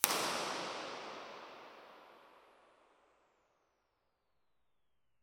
Here are the RIRs for the PL-11.
Test Position 3 – 80 ft
The Tectonic produced significantly more room excitation than the other devices, including the reference Dodecahedron loudspeaker.
Tectonic80ftIR.wav